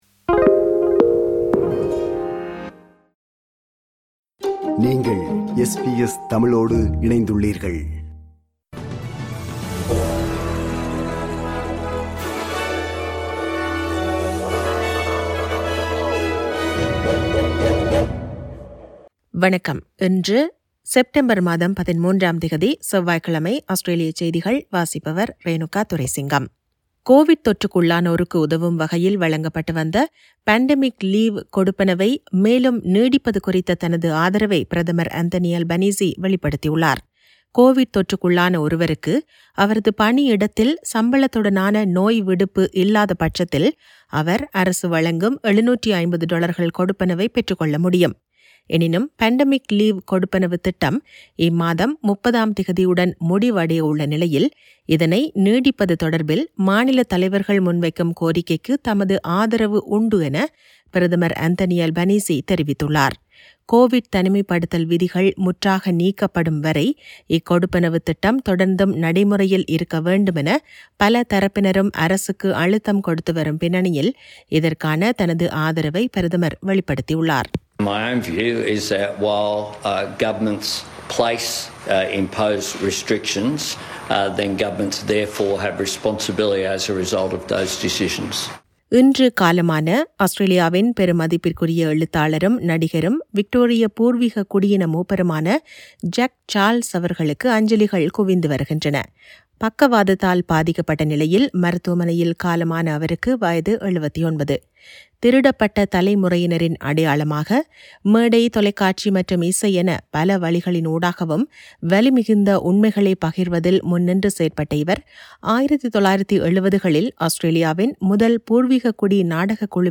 Australian news bulletin for Tuesday 13 Sep 2022.